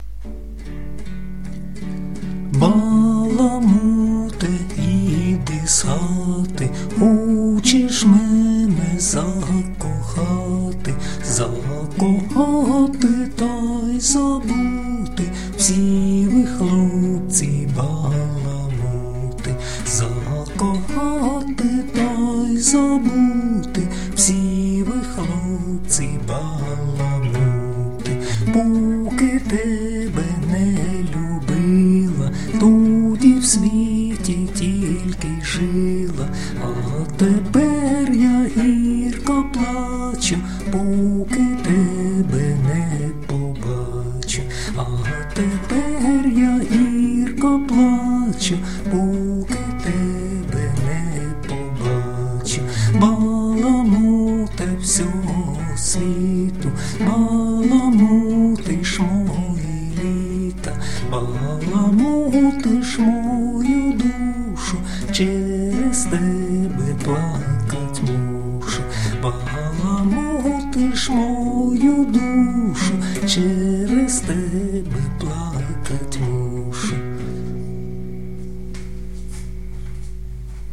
../icons/bellacha.jpg   Українська народна пiсня